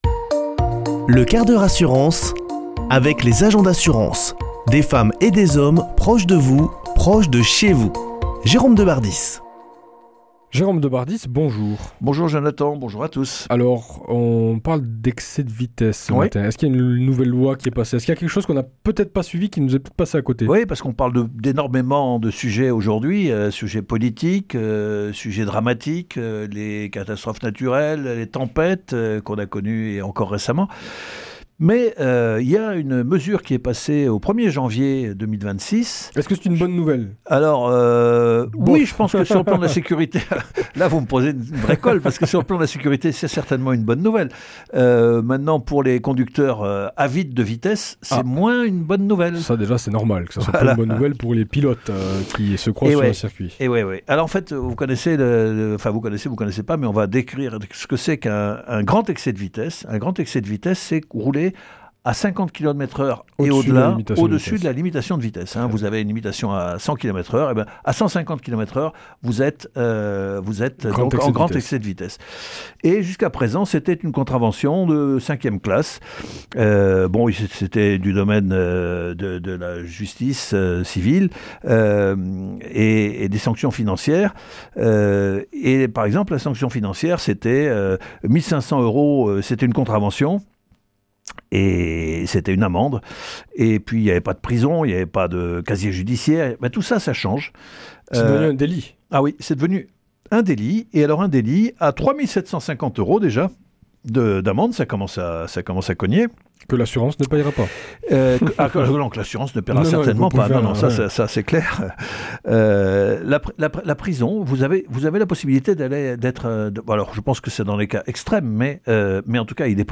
mardi 10 mars 2026 Chronique le 1/4 h assurance Durée 5 min
Une émission présentée par